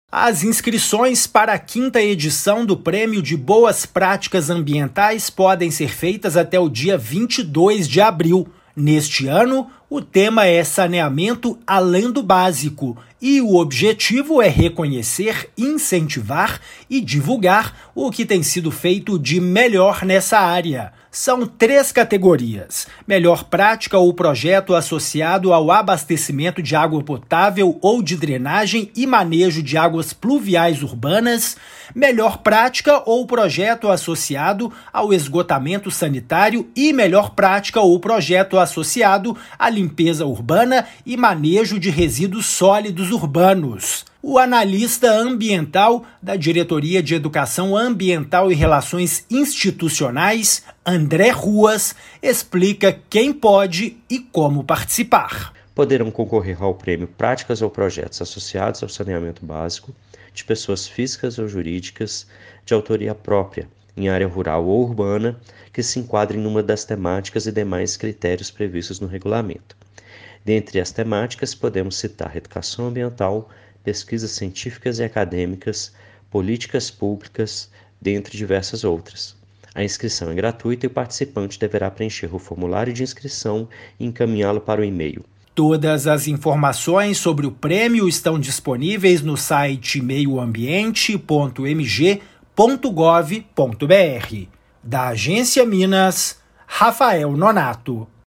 Interessados podem se inscrever no site da Semad. Ouça a matéria de rádio.
MATÉRIA_RÁDIO_PRÊMIO_SEMAD.mp3